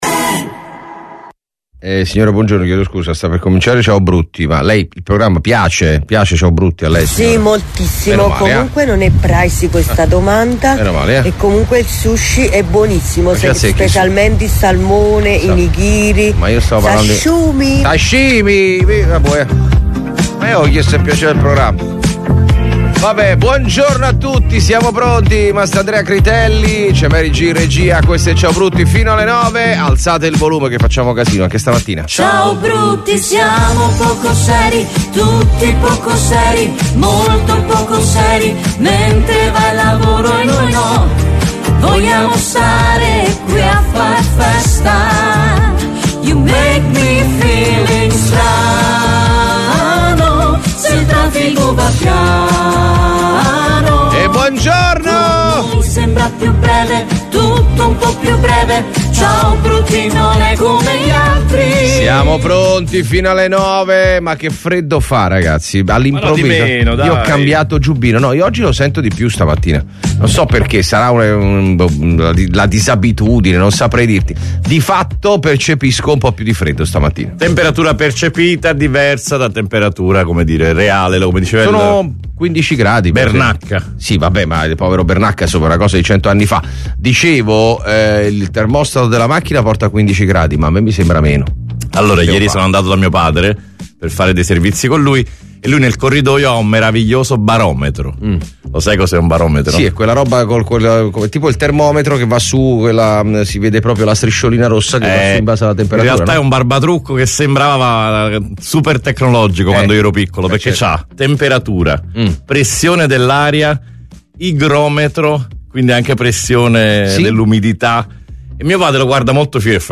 IL MORNING SHOW DI RADIO MARTE